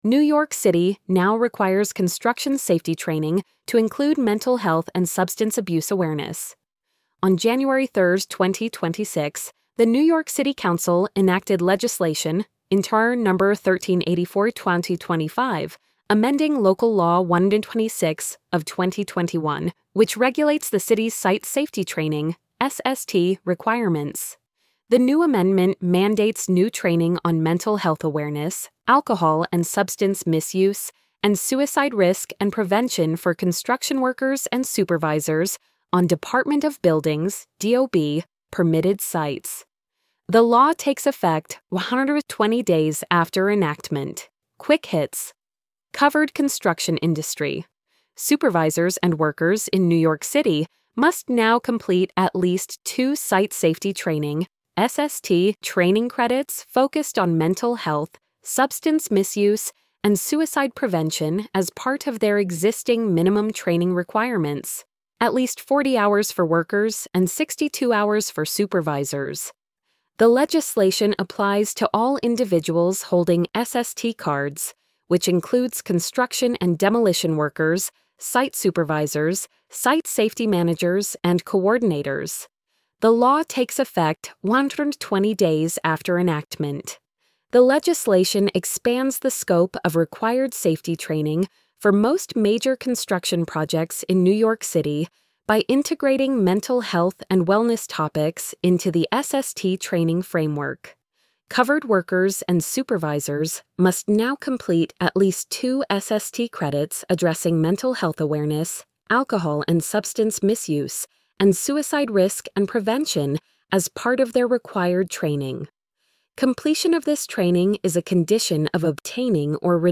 new-york-city-now-requires-construction-safety-training-to-include-mental-health-and-substance-abuse-awareness-tts.mp3